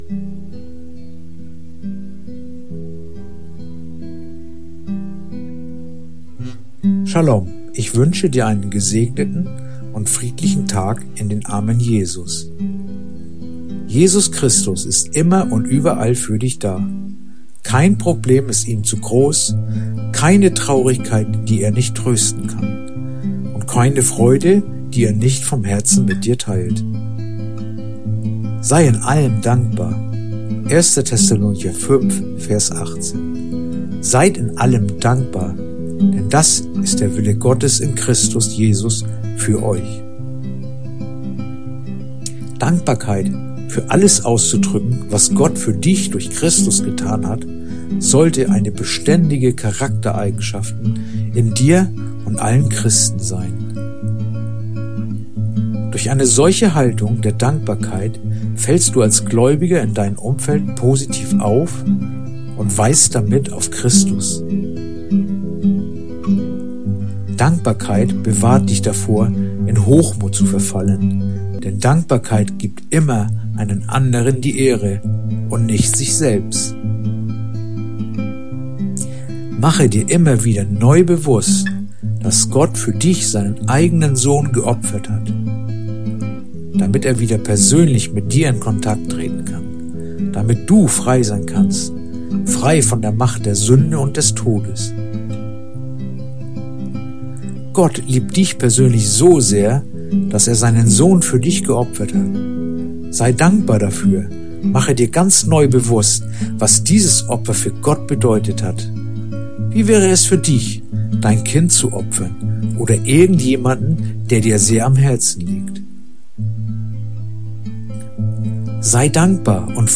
heutige akustische Andacht